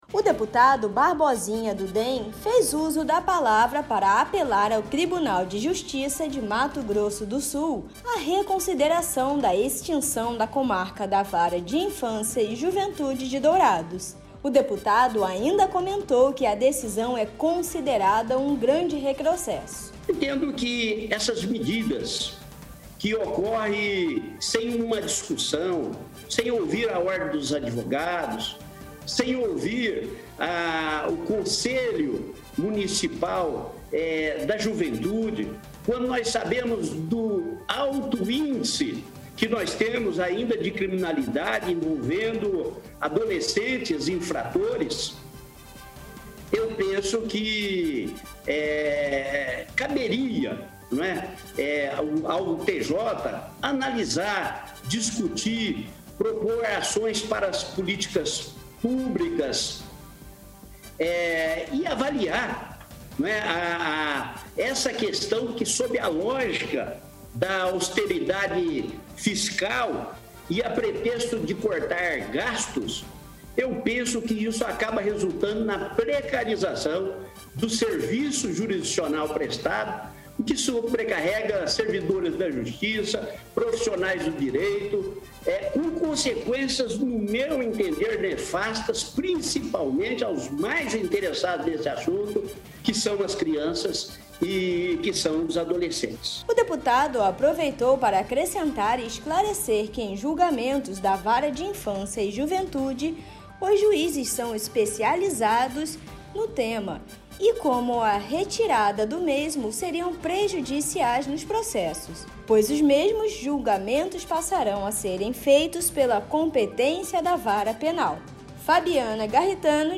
O deputado estadual Barbosinha (DEM) falou durante sessão plenária sobre decisão publicada em Diário Oficial do Tribunal de Justiça de Mato Grosso do Sul, na última terça-feira (22).